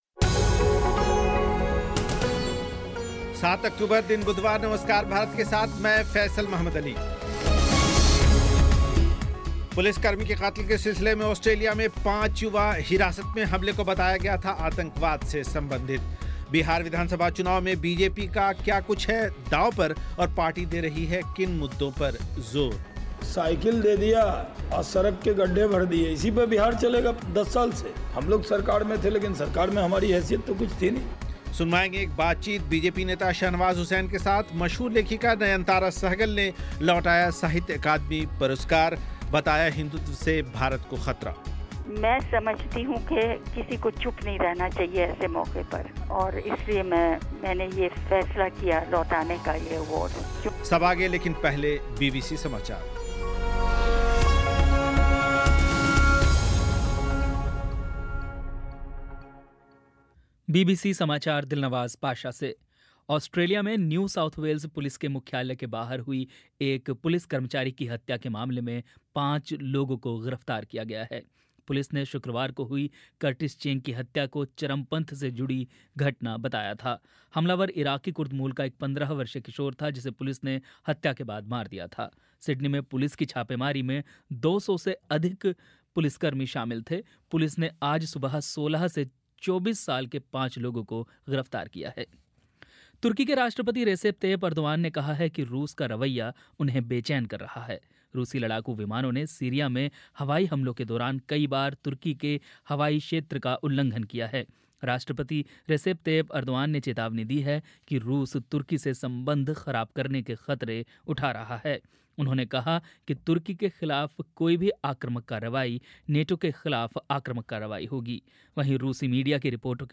एक बातचीत बीजेपी नेता शाहनवाज़ हुसैन के साथ
बातचीत एक गोसेवक से